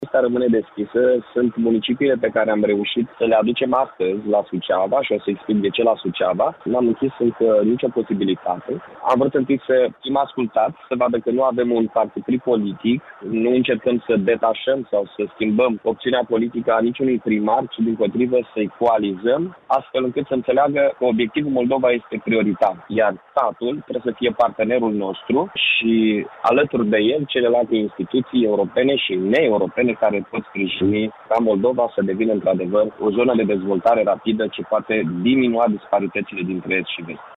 Primarul Iaşului, Mihai Chirica: